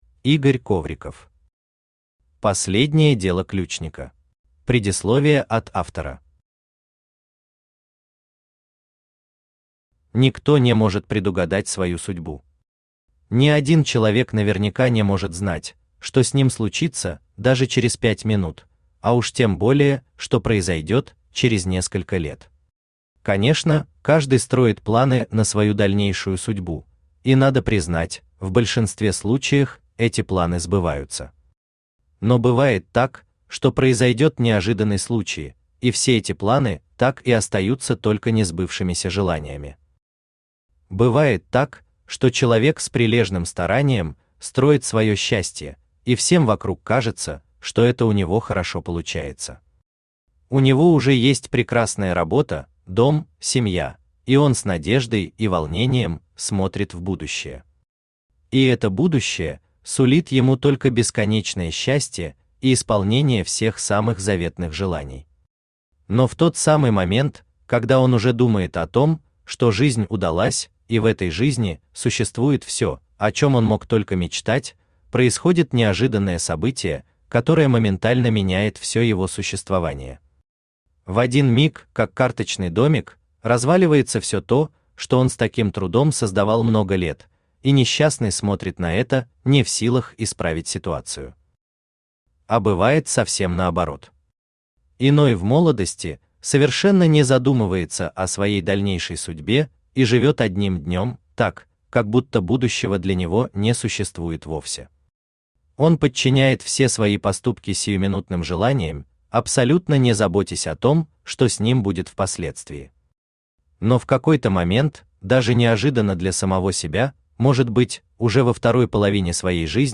Аудиокнига Последнее дело Ключника | Библиотека аудиокниг
Aудиокнига Последнее дело Ключника Автор Игорь Алексеевич Ковриков Читает аудиокнигу Авточтец ЛитРес.